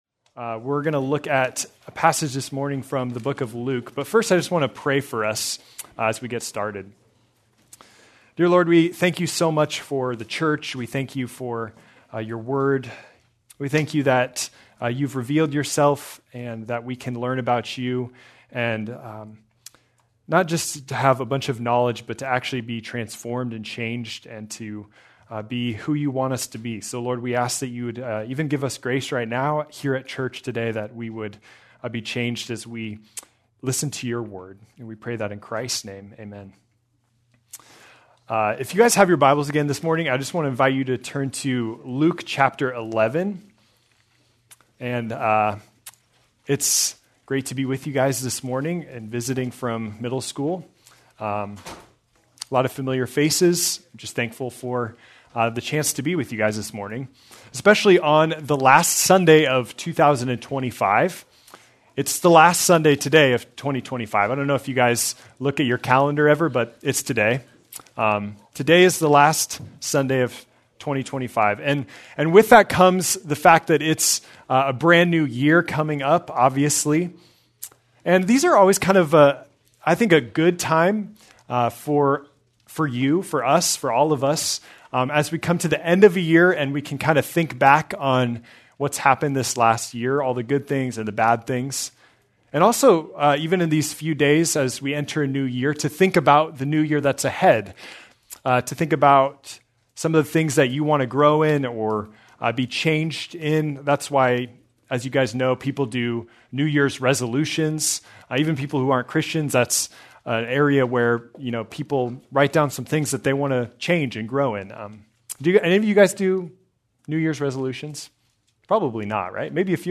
December 28, 2025 - Sermon | 180 Ministry | Grace Community Church